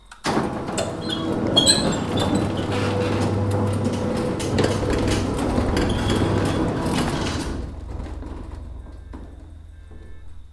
creaky garage door sound effect short.ogg
Original creative-commons licensed sounds for DJ's and music producers, recorded with high quality studio microphones.
creaky_garage_door_sound_effect_short_few.mp3